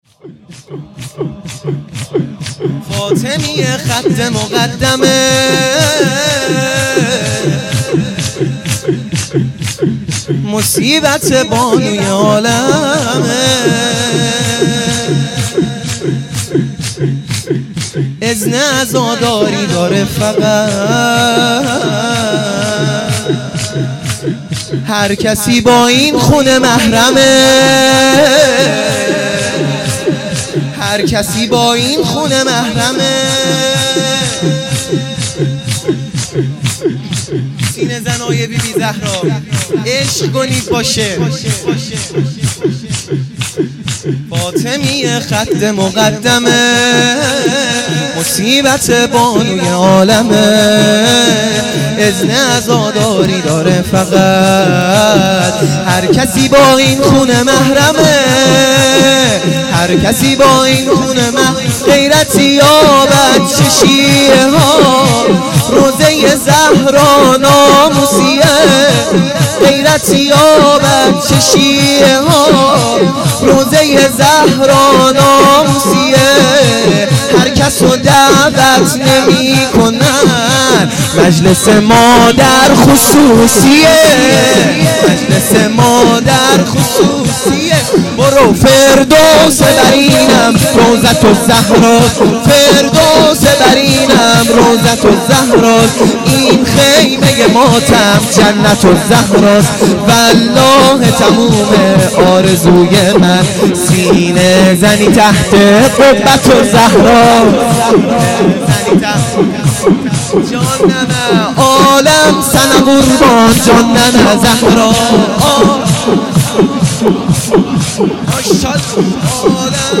شور | فاطمیه خط مقدمه